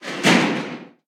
Cerrar una puerta de metal
puerta
metal
Sonidos: Hogar